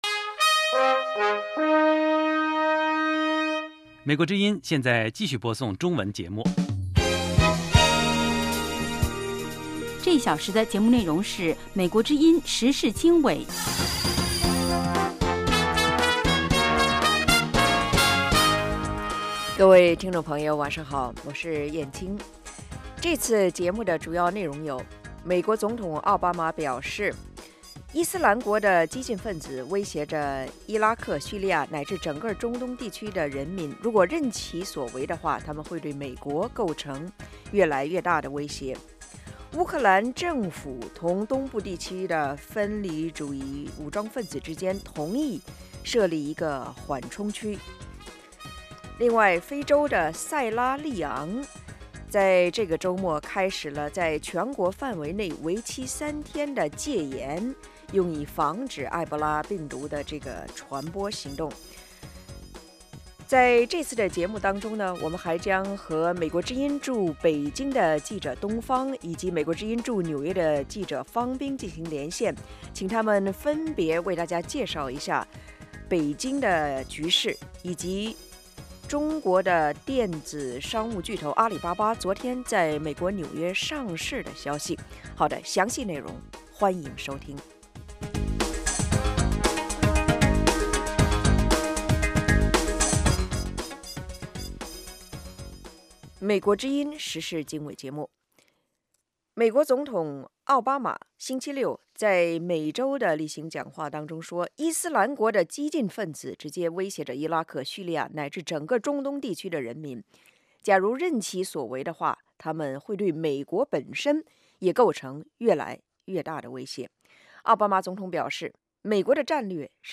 晚7-8点广播节目